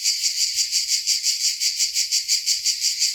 sounds_cicada_06.ogg